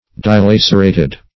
Search Result for " dilacerated" : The Collaborative International Dictionary of English v.0.48: Dilacerate \Di*lac"er*ate\, v. t. [imp.
dilacerated.mp3